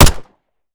Home gmod sound weapons papa90
weap_papa90_fire_plr_01.ogg